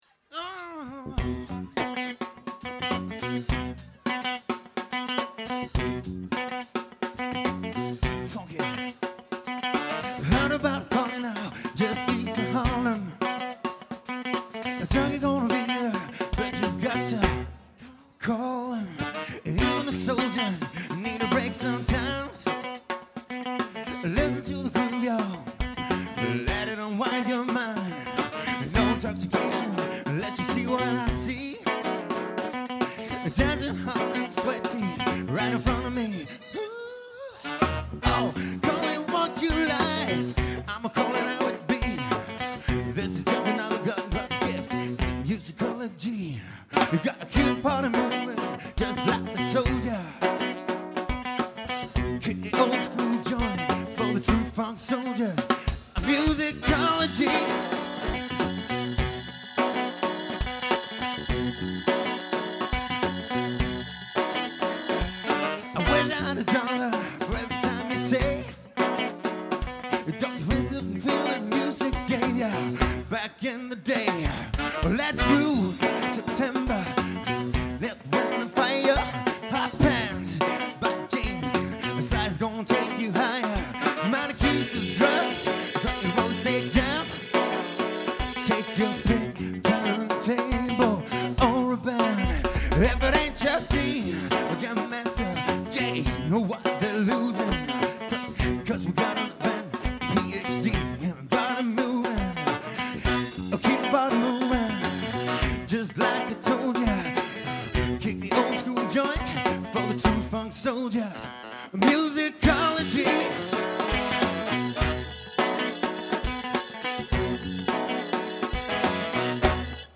Opptak fra konsert på Vista 20.02.05